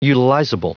Prononciation du mot utilizable en anglais (fichier audio)
Prononciation du mot : utilizable